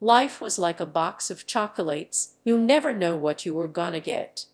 Audio_TTS_fastspeech.wav